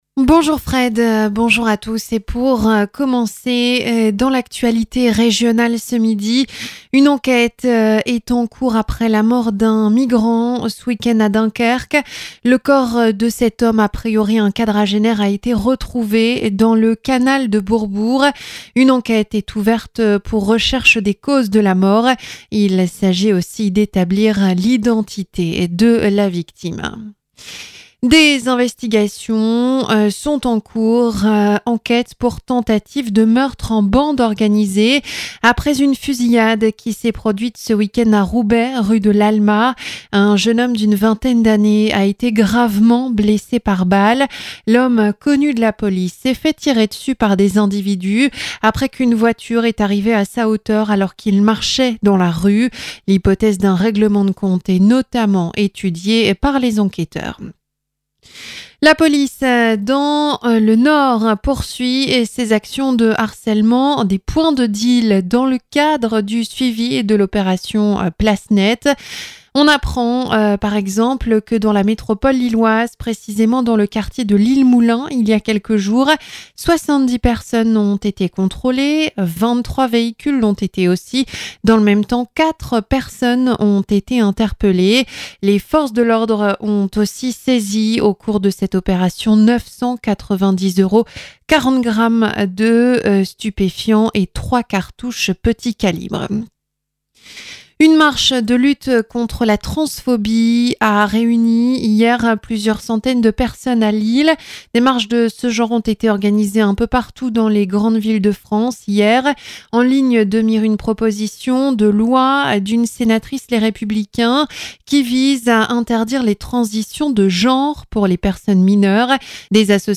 Dans l'actualité de ce lundi 6 mai à 12h sur MonaFm